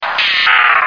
P3D-Legacy / P3D / Content / Sounds / Cries / 452.wav